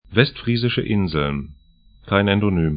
Aussprache
Westfriesische Inseln 'vɛstfri:zɪʃə 'ɪnzln Nederlandse Waddeneilanden 'ne:dɐlantsə 'vadənailandən nl Inseln / islands 53°26'N, 05°33'E